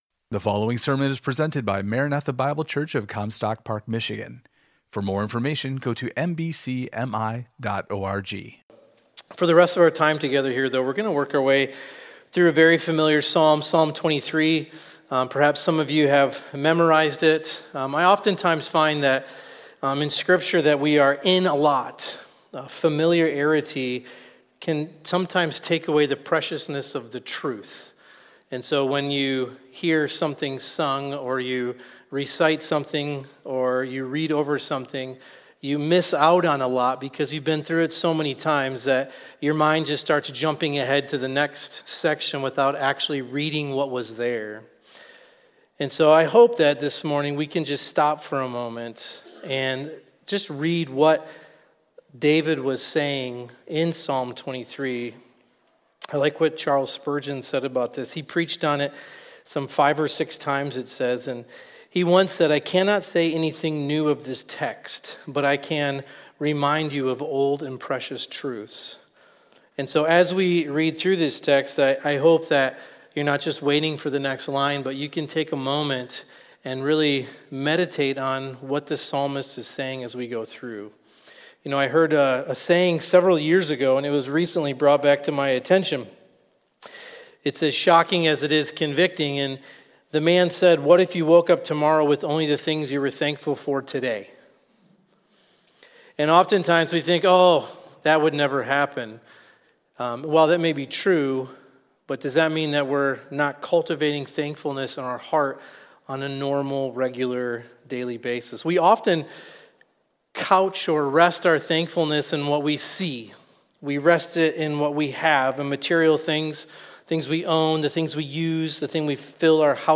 Thanksgiving Service